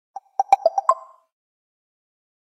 Category: Notification Ringtones